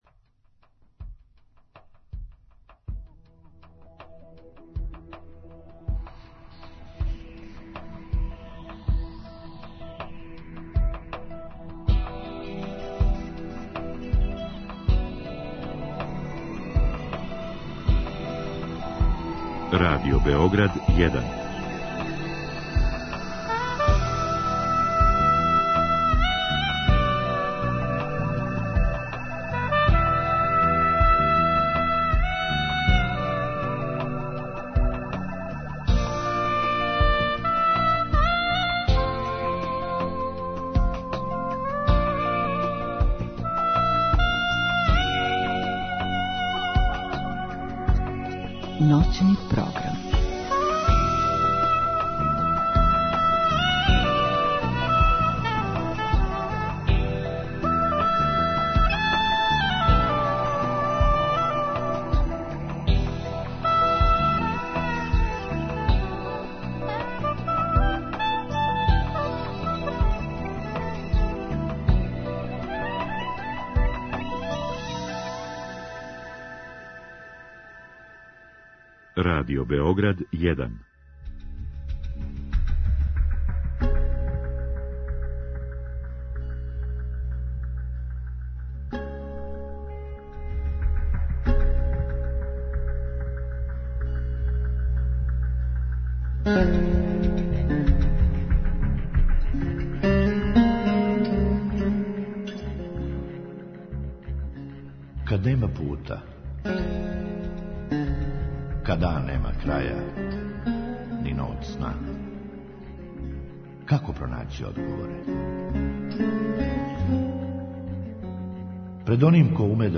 Други сат предвиђен је за укључење слушалаца, који у директном програму могу поставити питање гошћи.